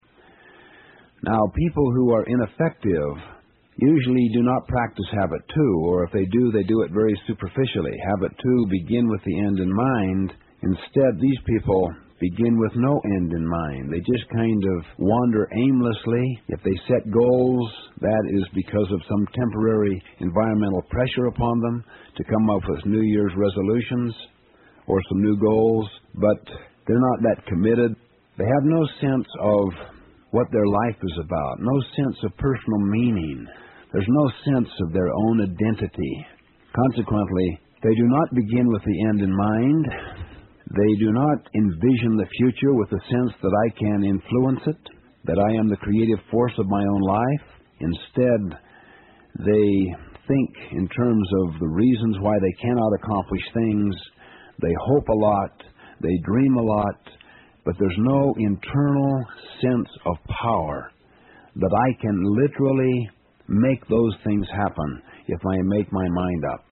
有声畅销书：与成功有约03 听力文件下载—在线英语听力室